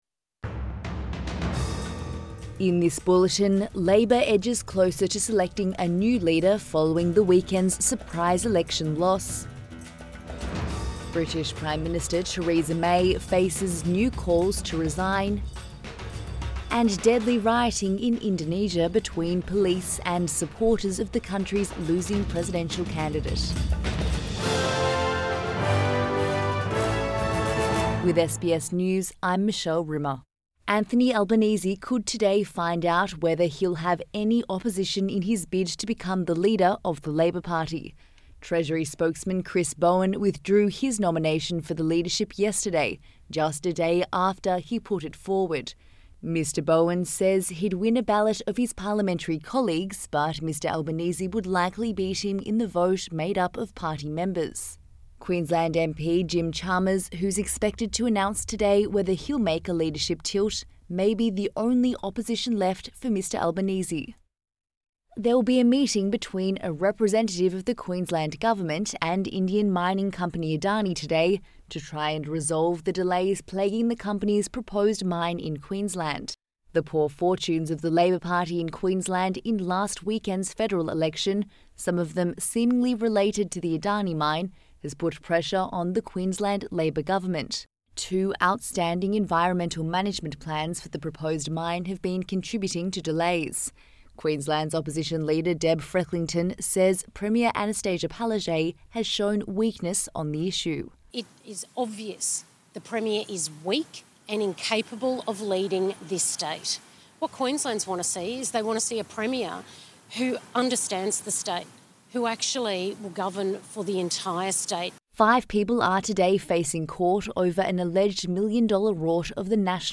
AM bulletin 23 May 2019